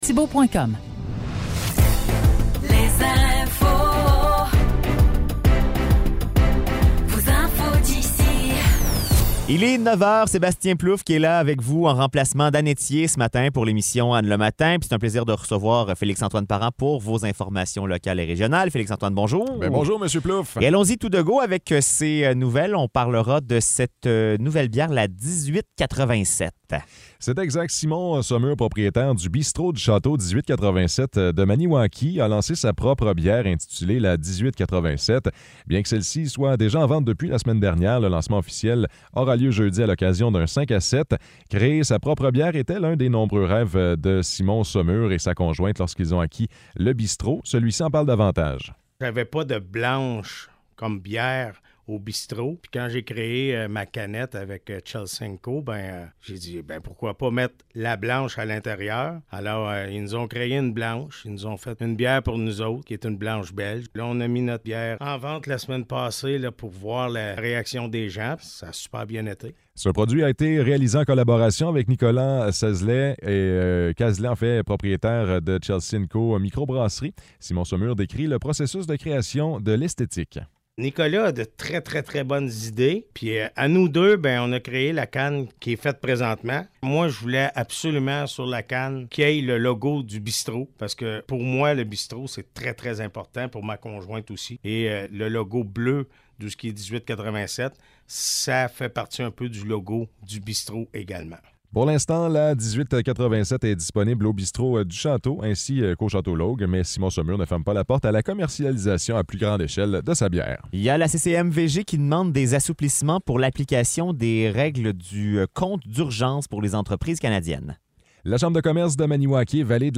Nouvelles locales - 28 novembre 2023 - 9 h